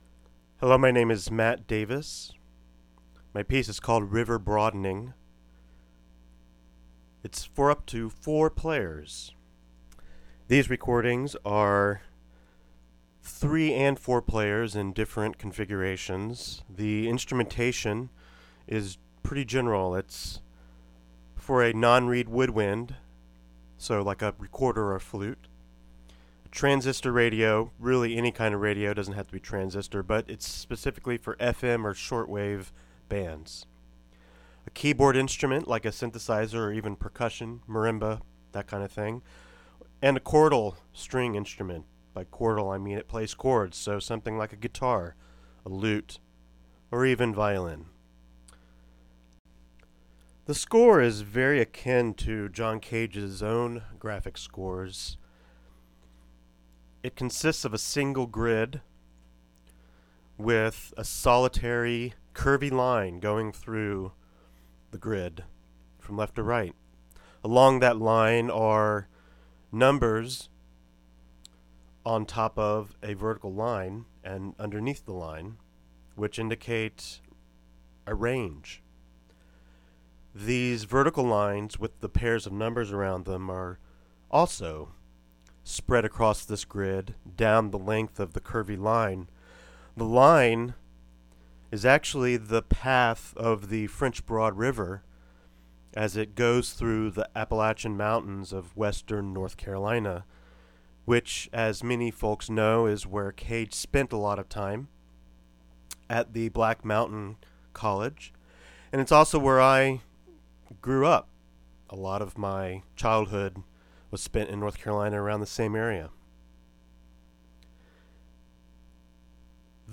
A score for up to four players: a non-reed wood...